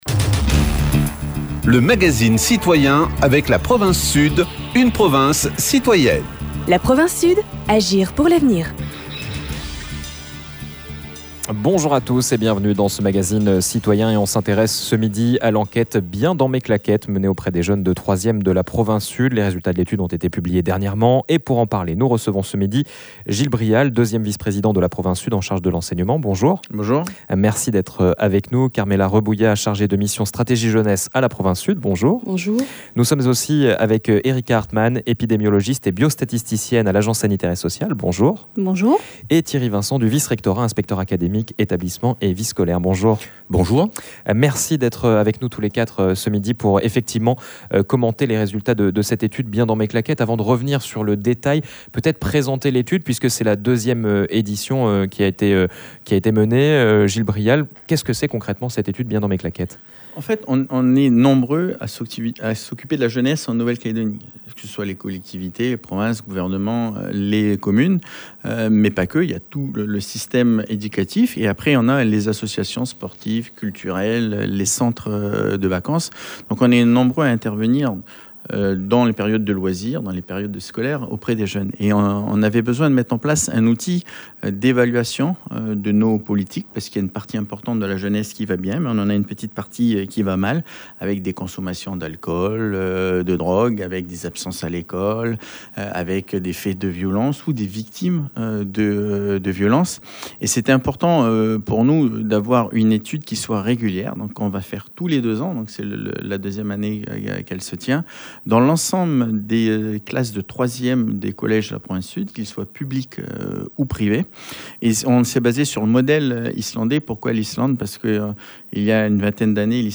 Gil BRIAL deuxième vice-président de la Province Sud en charge de l’enseignement